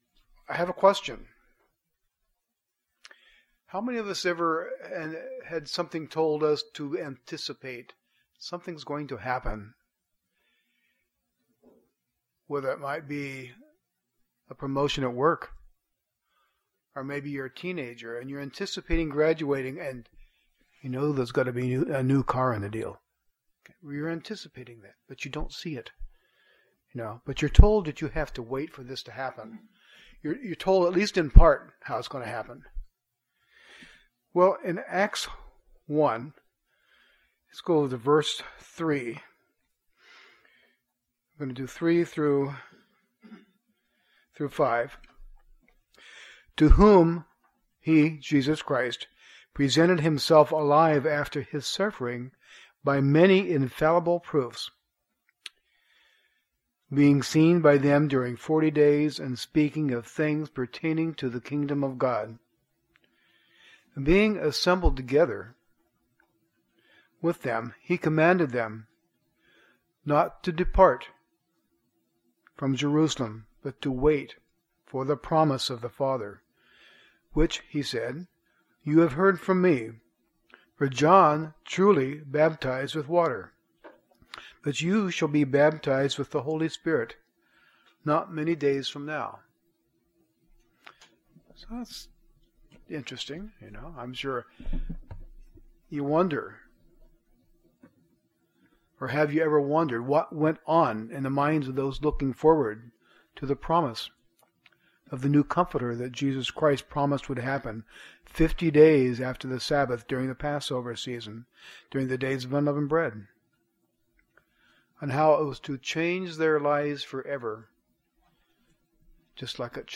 Sermons
Given in Northwest Arkansas